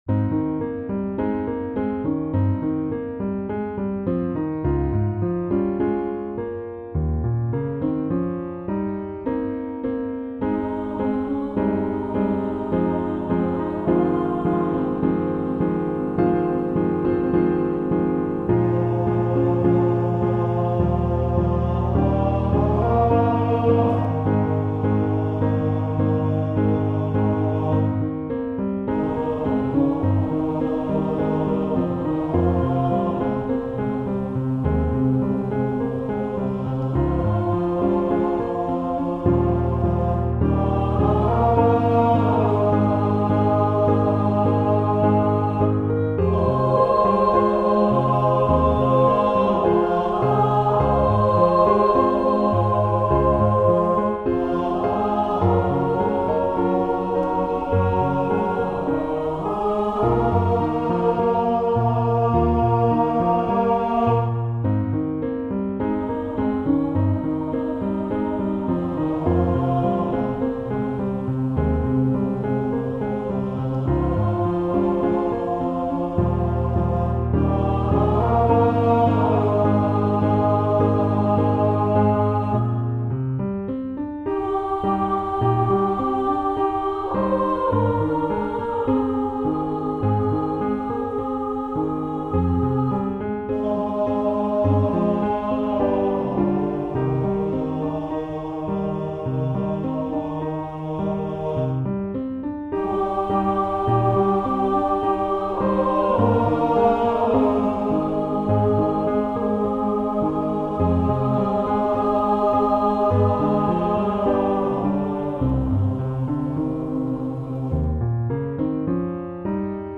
Voicing/Instrumentation: SAB